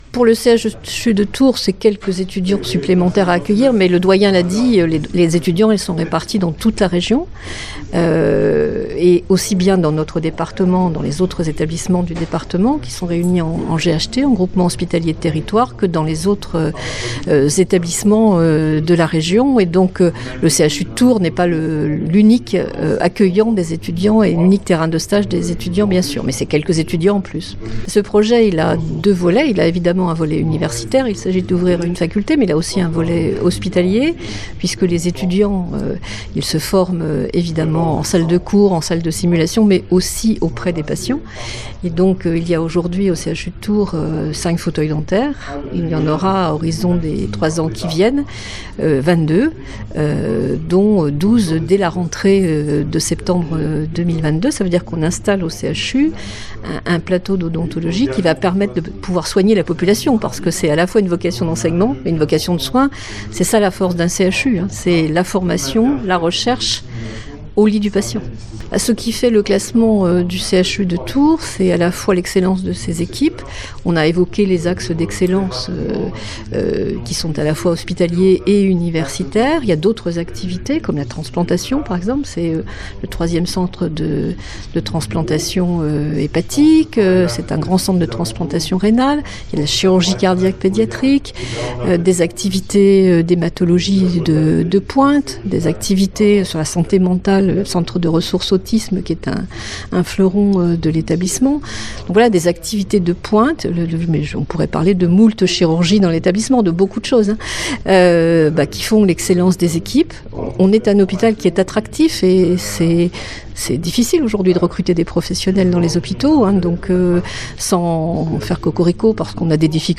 Conférence de presse sur le renforcement des formations en santé dans notre région